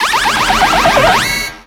Cri de Magnézone dans Pokémon X et Y.